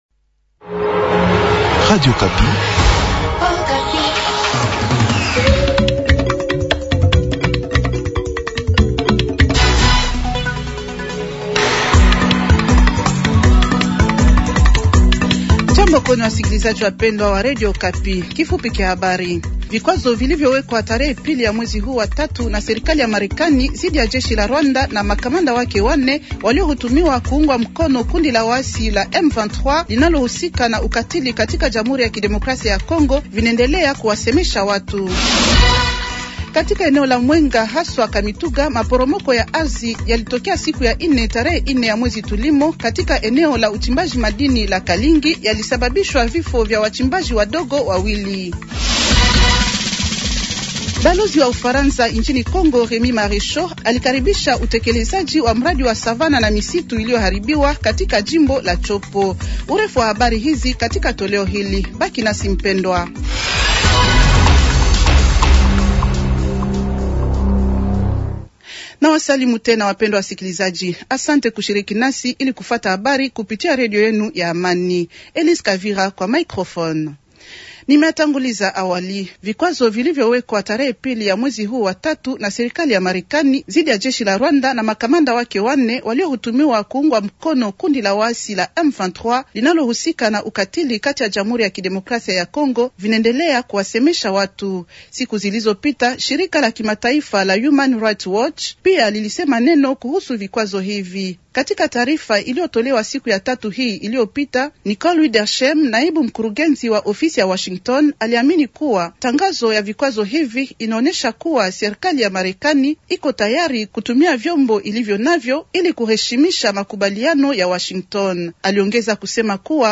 Journal de vendredi matin 060326